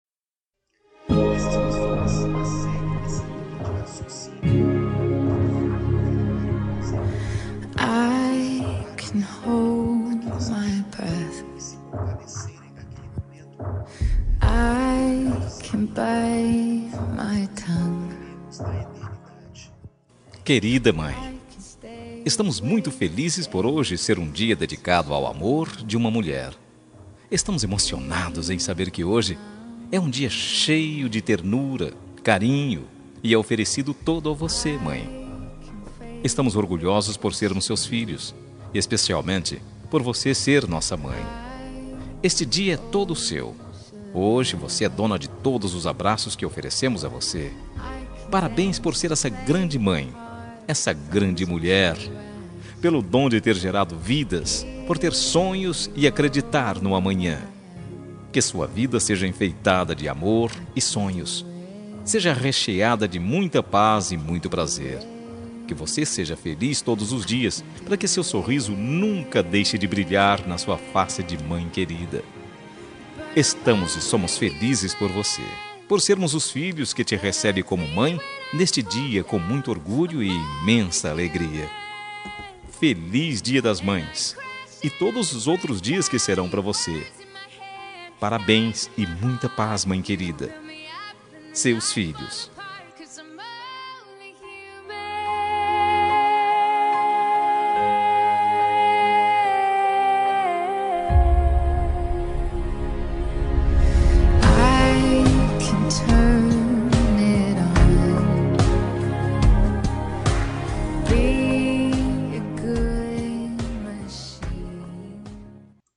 Dia das Mães – Para minha Mãe – Voz Masculina – Plural – Cód: 6524